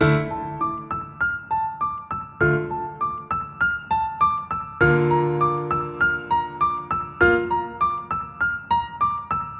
原声钢琴71100bpm
描述：D小调的HipHop/Rap钢琴循环曲。